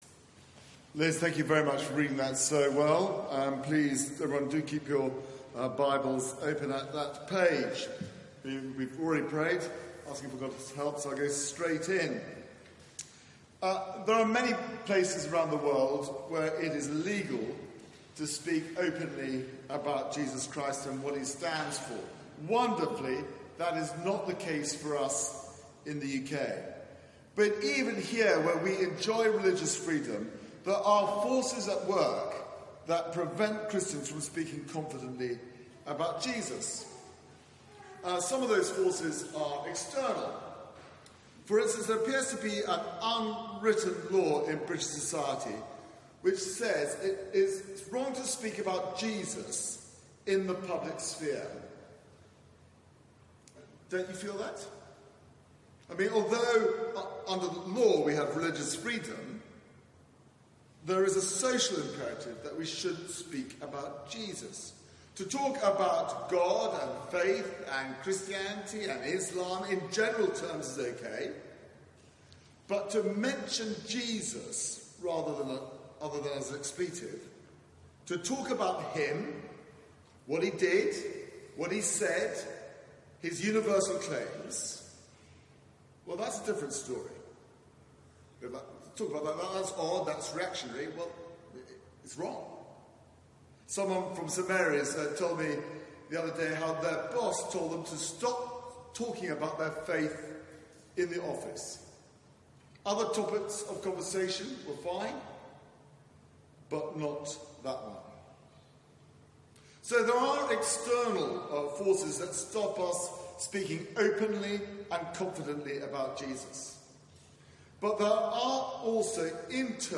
Media for 4pm Service on Sun 19th Aug 2018 16:00 Speaker
Sermon